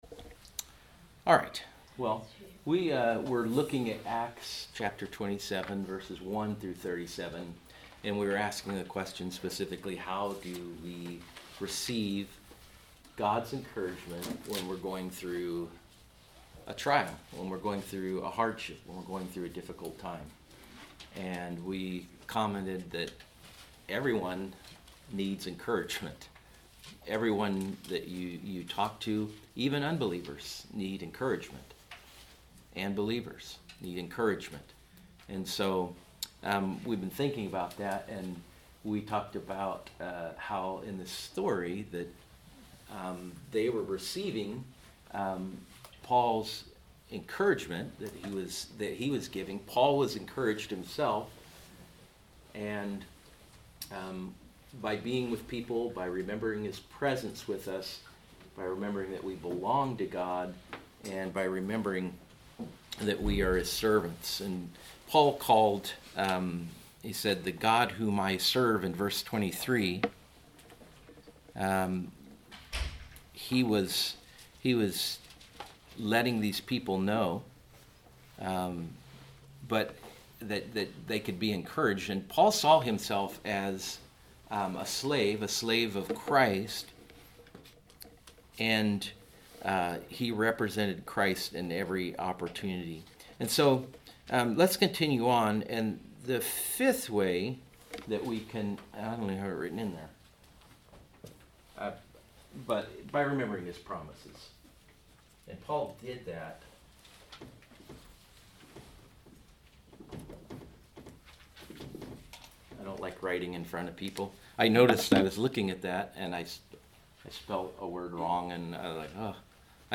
Sermons | New Hope Baptist Church